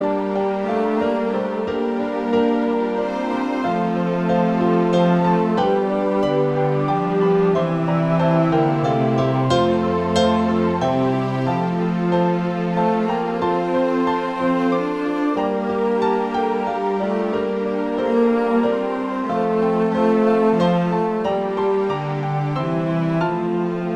Christmas Carol